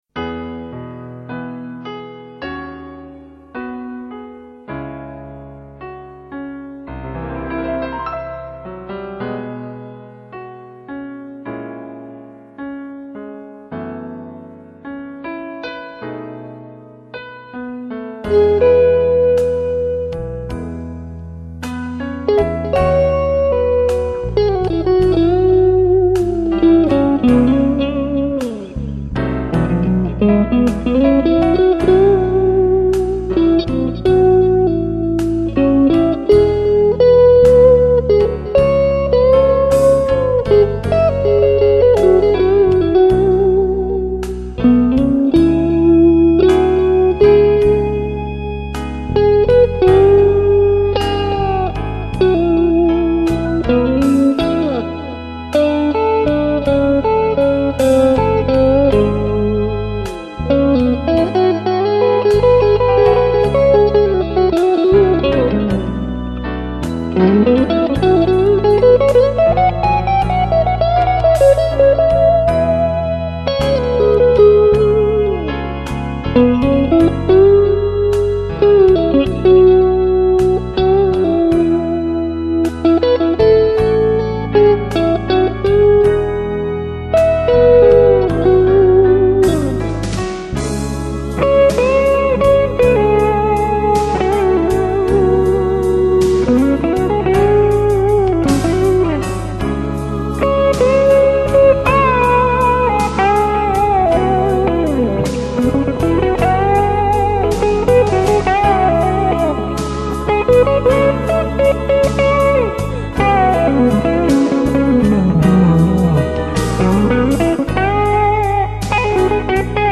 PISTES AUDIO (guitares & basse) + MIDI
Georgia (Soul . sur BT Ray Charles "Georgia on my mind")